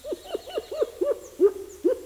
Animal Sounds
Red Capped Mangabey 584